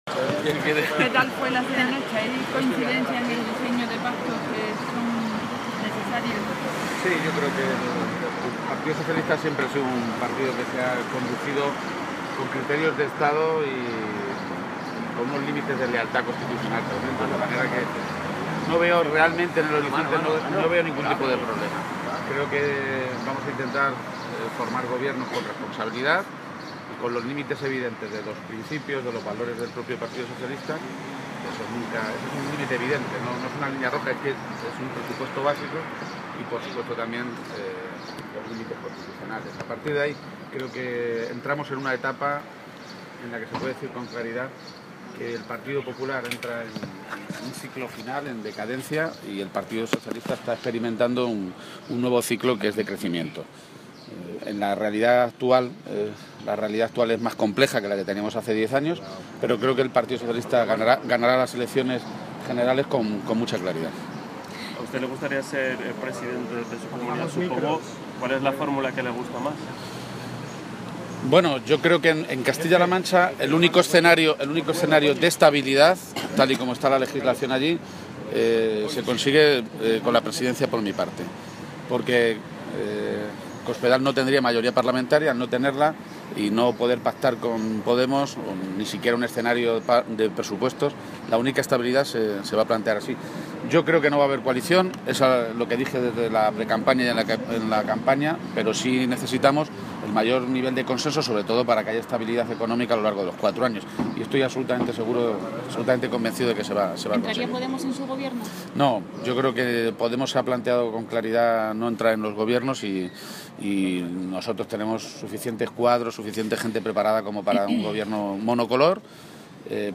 Así lo ha dicho en declaraciones a los medios de comunicación, esta mañana, en Madrid, poco antes de que comenzara la reunión del Comité Federal del PSOE en su sede de la madrileña calle de Ferraz.
Cortes de audio de la rueda de prensa